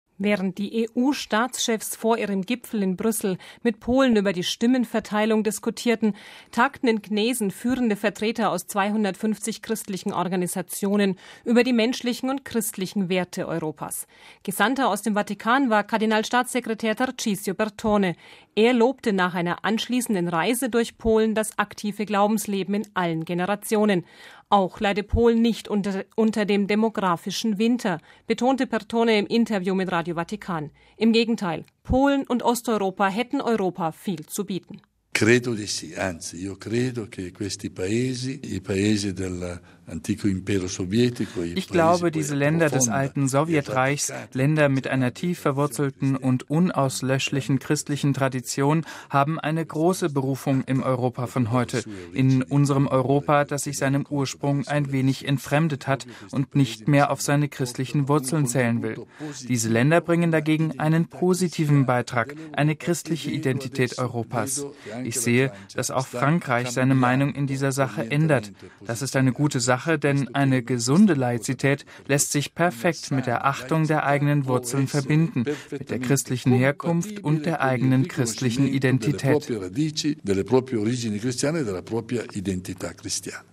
Auch leide Polen nicht unter dem „demographischen Winter“, betonte Bertone im Interview mit Radio Vatikan. Im Gegenteil, Polen und Osteuropa hätten Europa viel zu bieten.